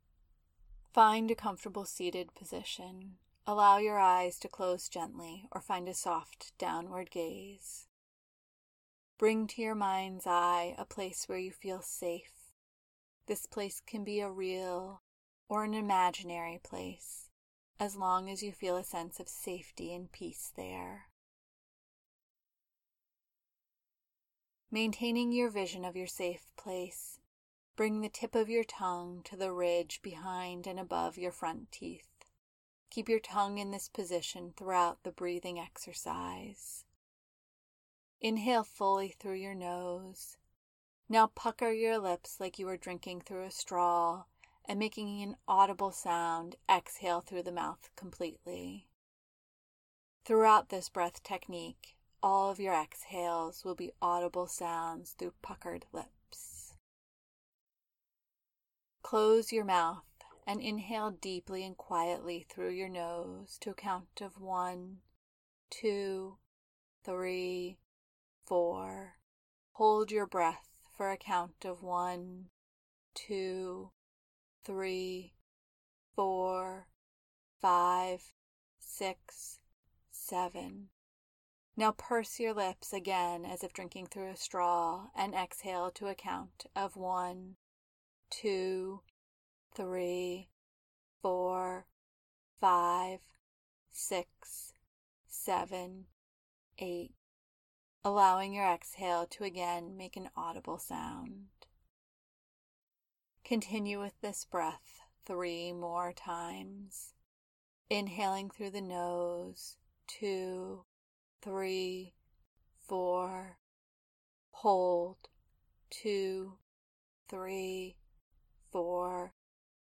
4-7-8 Guided Breathwork
Here is the guided audio for this pranayama.